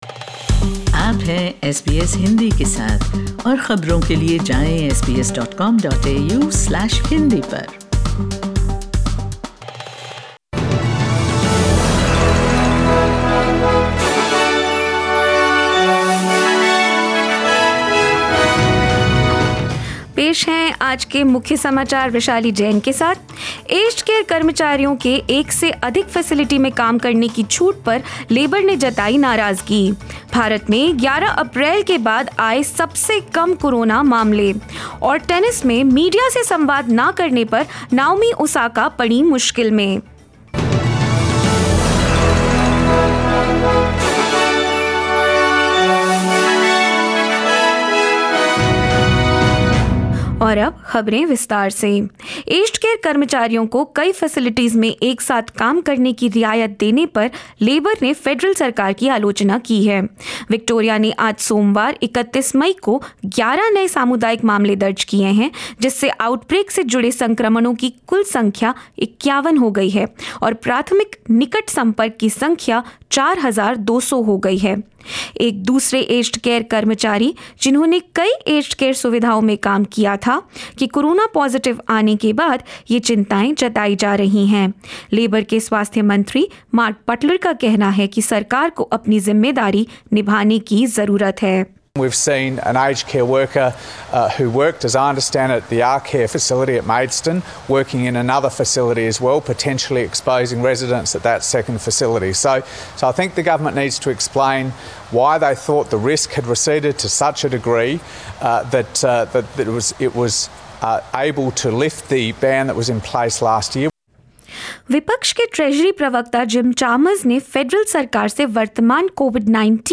In this latest SBS Hindi News bulletin of Australia and India: Victoria reports 11 coronavirus cases on Monday as the state enters its fourth day of lockdown; India records its lowest daily Covid-19 cases since April 11 and more. 30/5/21